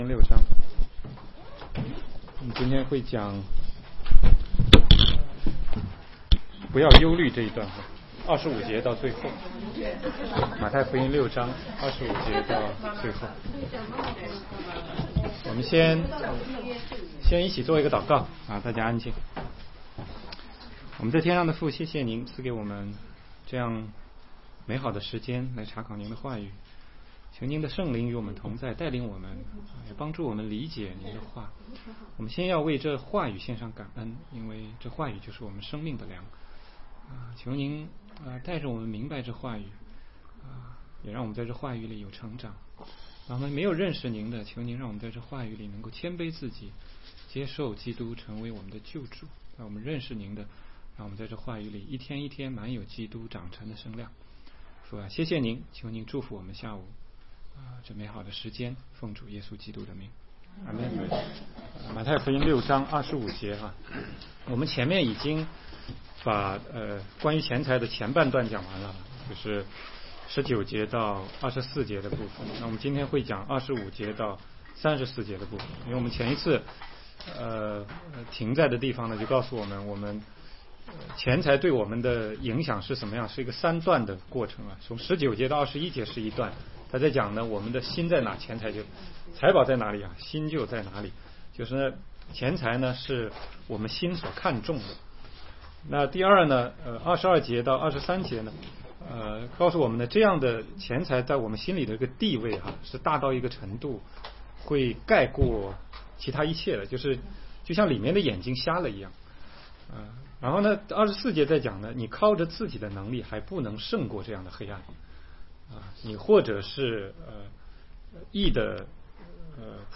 16街讲道录音 - 马太福音6章25-34节